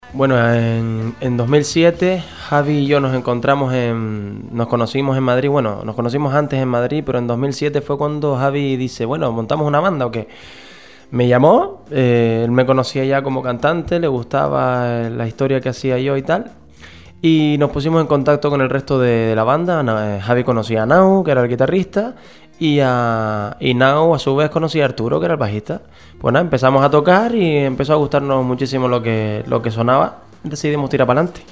Ivan, integrante del grupo Efecto Pasillo, visito los estudios de Radio Planeta Gran Canaria para hacernos vibrar co su nuevo lanzamiento discografico, En el Aire, el cual tras triunfar en territorio peninsular llega a canarias para arrasar este verano.